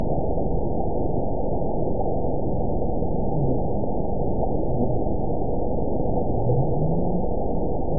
event 922100 date 12/26/24 time 11:00:53 GMT (5 months, 3 weeks ago) score 9.47 location TSS-AB04 detected by nrw target species NRW annotations +NRW Spectrogram: Frequency (kHz) vs. Time (s) audio not available .wav